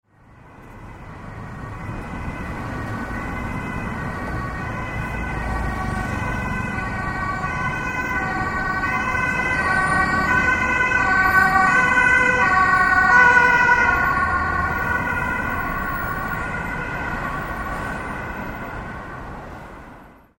Emergency Car In Traffic Sound Effect
Description: Emergency car in traffic sound effect. European emergency vehicle siren. Ambulance passing through city traffic. City urban sounds.
Emergency-car-in-traffic-sound-effect.mp3